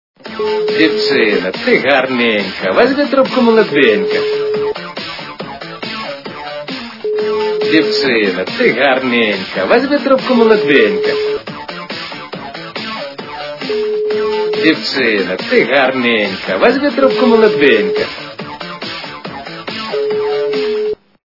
» Звуки » Люди фразы » Голос - Дивчина, ты гарнэнька визмы трубку
При прослушивании Голос - Дивчина, ты гарнэнька визмы трубку качество понижено и присутствуют гудки.